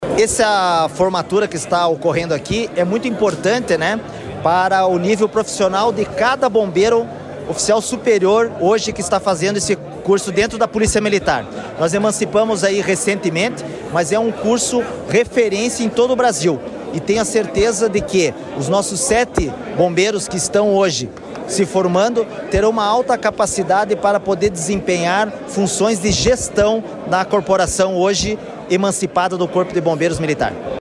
Sonora do comandante-geral do Corpo de Bombeiros Militar do Paraná, coronel Manoel Vasco de Figueiredo Junior, sobre a formatura de novos oficiais da PM e do Corpo de Bombeiros